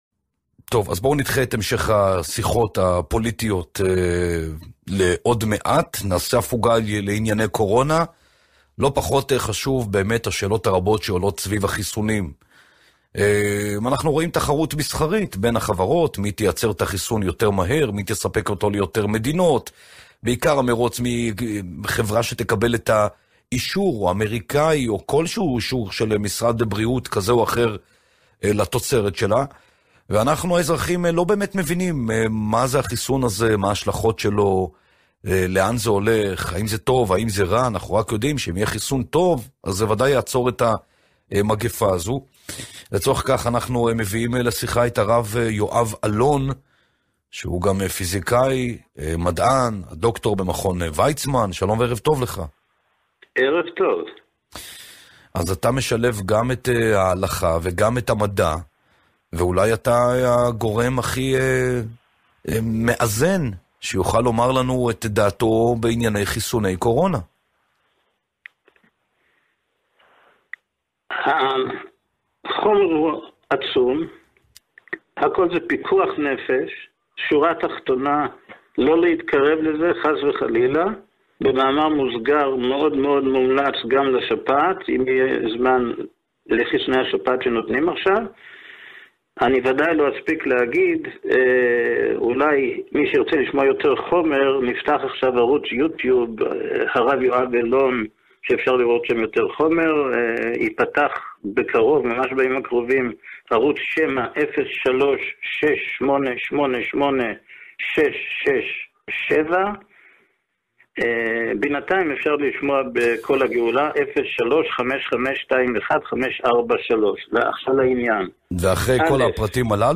בראיון למהדורה המרכזית: אנחנו לפני שואה, להתרחק מחיסוני הקורונה